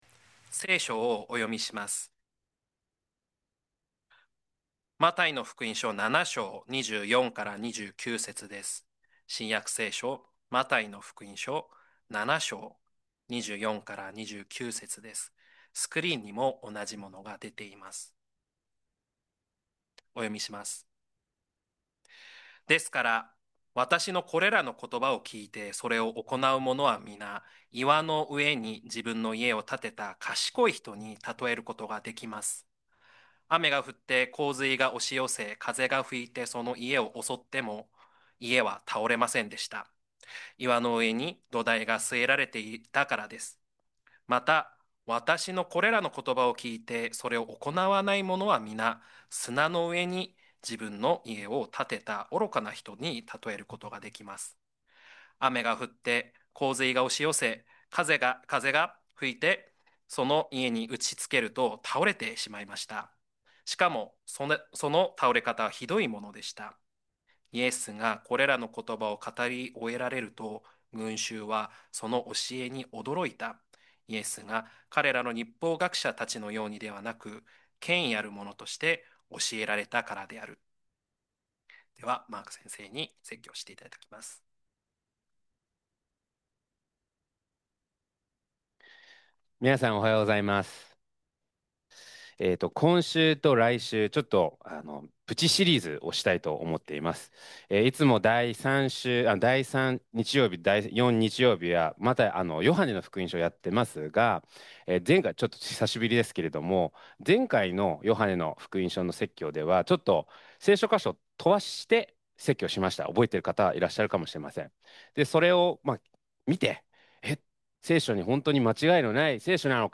2025年12月28日礼拝 説教 「聖書のみ」によって生きるとは – 海浜幕張めぐみ教会 – Kaihin Makuhari Grace Church